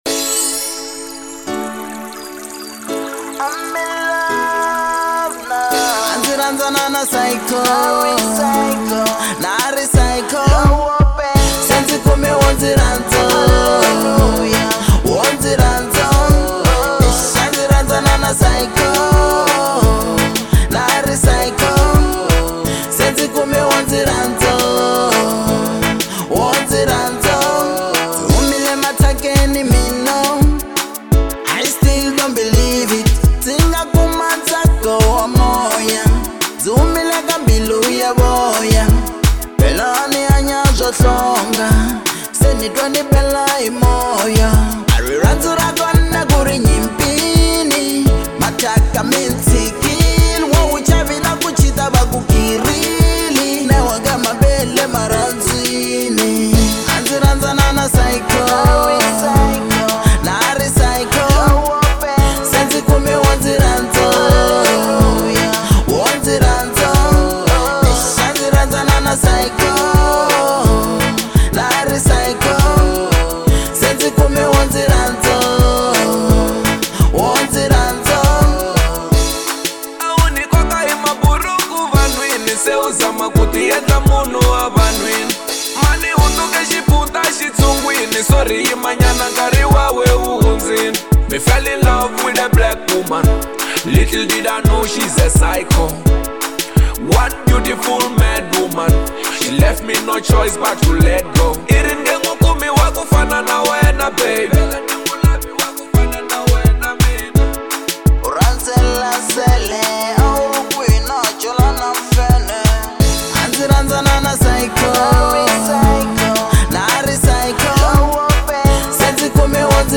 Genre : Afro Pop